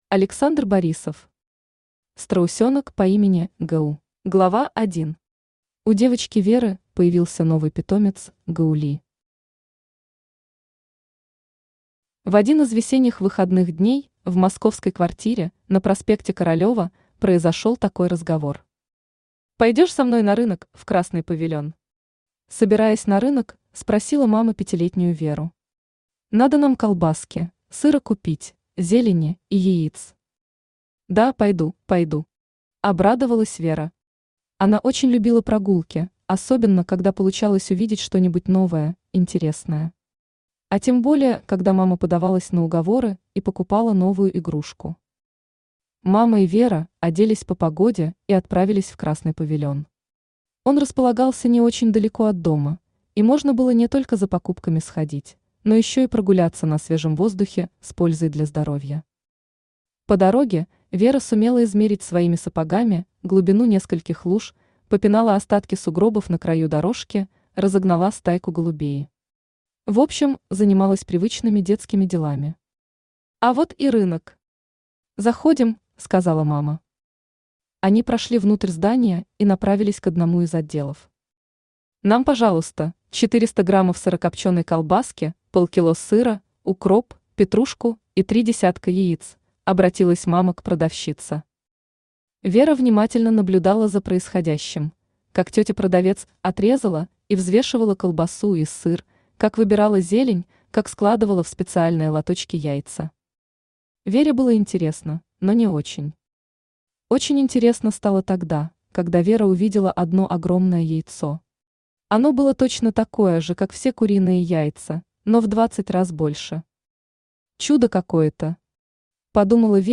Аудиокнига Страусенок по имени Гу | Библиотека аудиокниг
Aудиокнига Страусенок по имени Гу Автор Александр Борисов Читает аудиокнигу Авточтец ЛитРес.